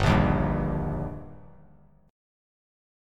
Gm#5 chord